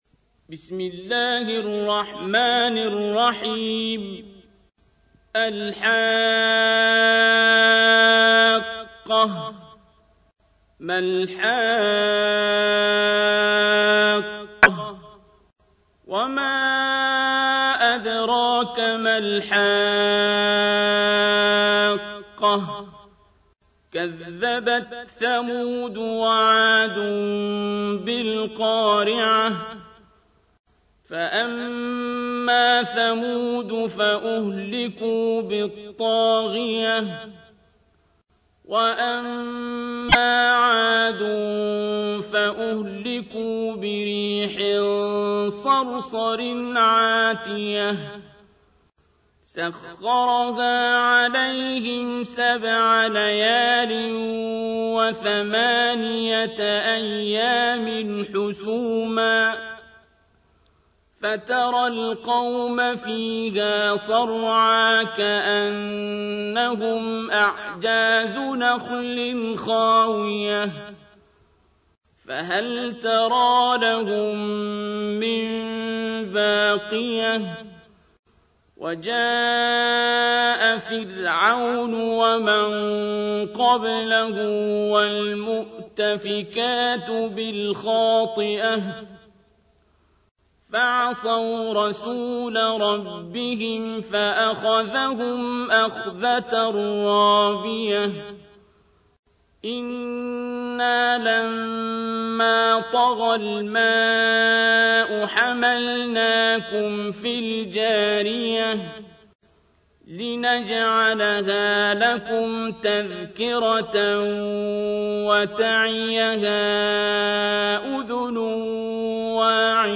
تحميل : 69. سورة الحاقة / القارئ عبد الباسط عبد الصمد / القرآن الكريم / موقع يا حسين